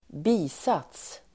Uttal: [²b'i:sat:s]